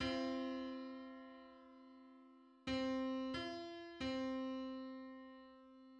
Just: 665/512 = 452.65 cents.
Public domain Public domain false false This media depicts a musical interval outside of a specific musical context.
Six-hundred-sixty-fifth_harmonic_on_C.mid.mp3